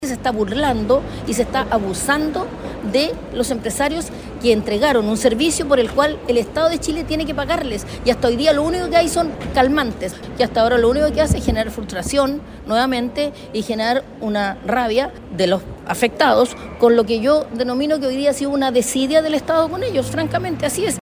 En ese sentido, la evaluación que hace la parlamentaria es que la deuda no se puede explicar sino -dijo- en un actuar negligente de los responsables de pagar los dineros.